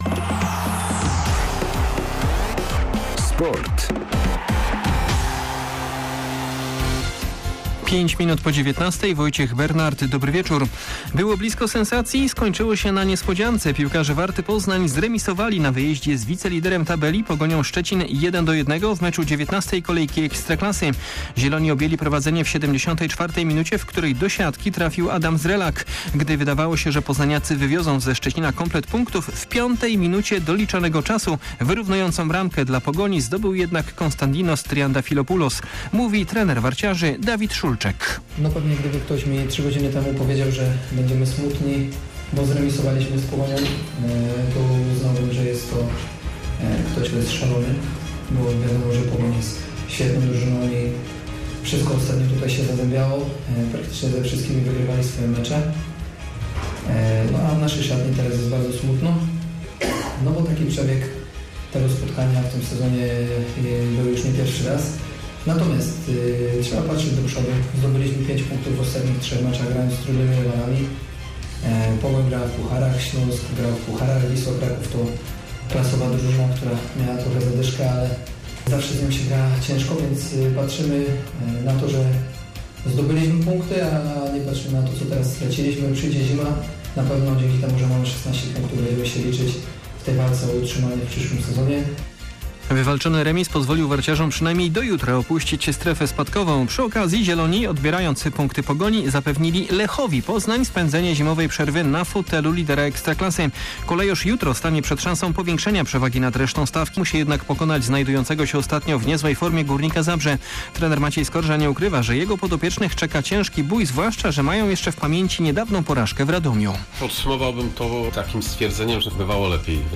18.12.2021 SERWIS SPORTOWY GODZ. 19:05